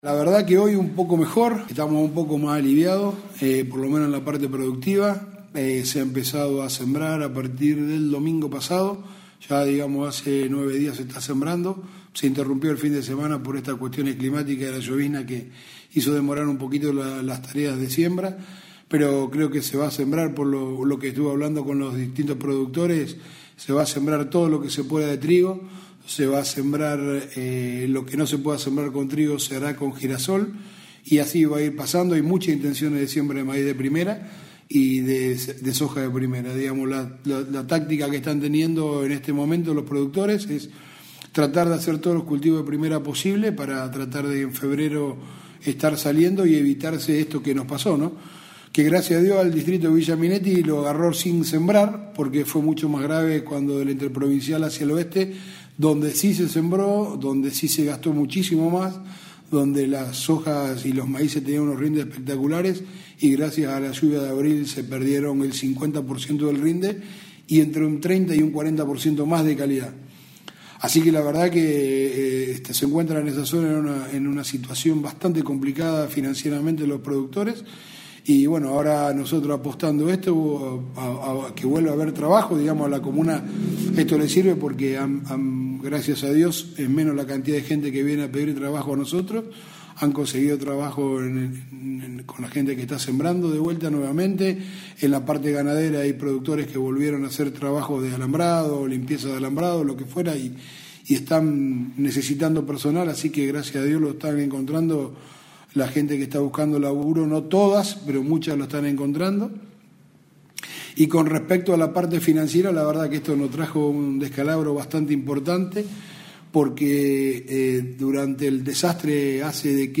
Por otra parte, el presidente comunal de Villa Minetti indicó cómo es el panorama actual tanto en el casco urbano como en la zona rural, luego de las complicaciones que sufrió el distrito por la reciente inundación: